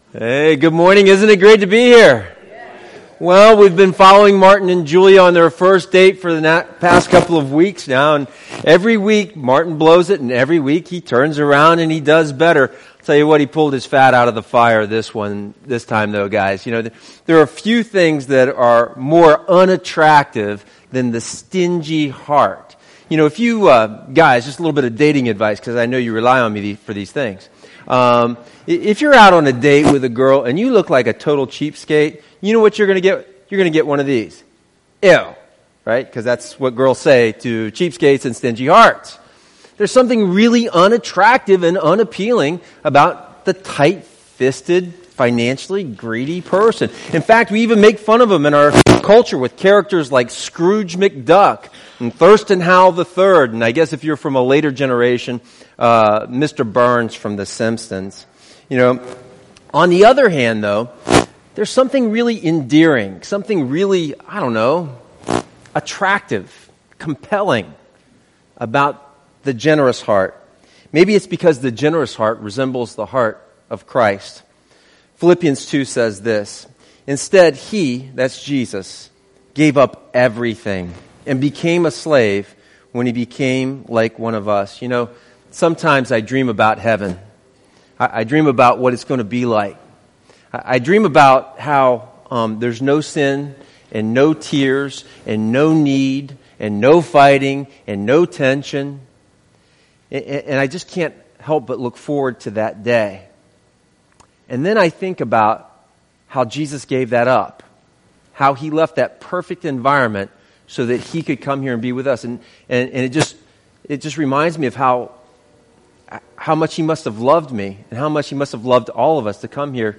A sermon on giving for New Testament disciples covering giving consistently, confidentially, and cheerfully.